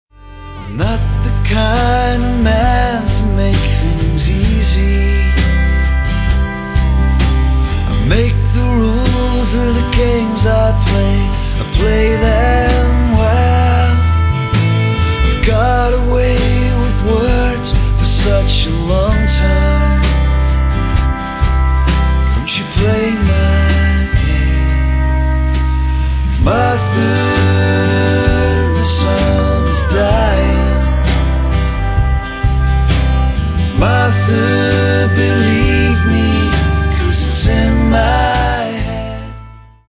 Vocals, Acoustic Guitar
Bass, Vocals, Accordion, Harmonium
Electric Guitar, Vocals, Pedal Steel
Drums, Vocals, Percussion, Tack Piano